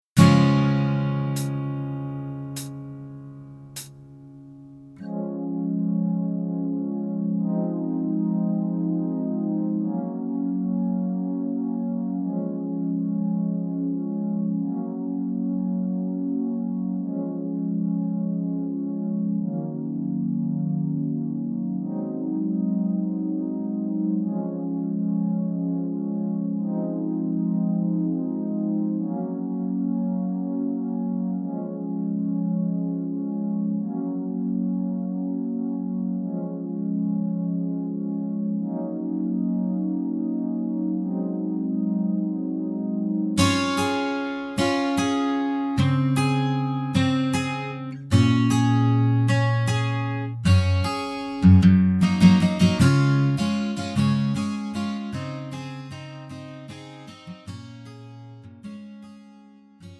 음정 -1키
장르 가요 구분 Pro MR